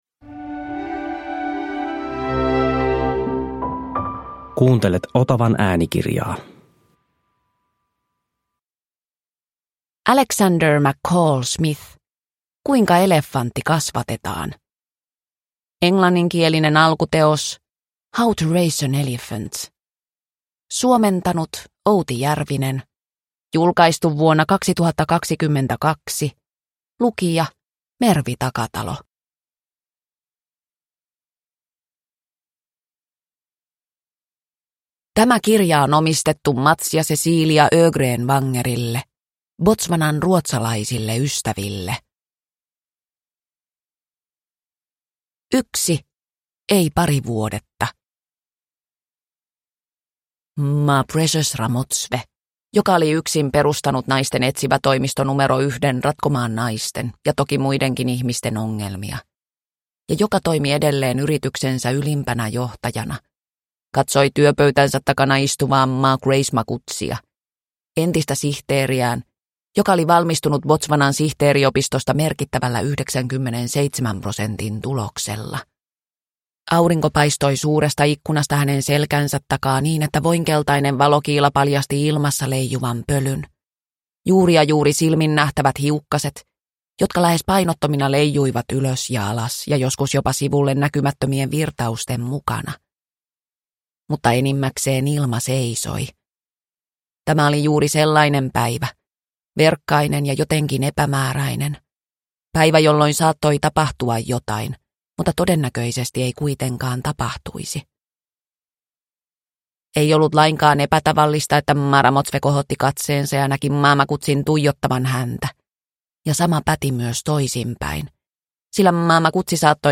Kuinka elefantti kasvatetaan – Ljudbok – Laddas ner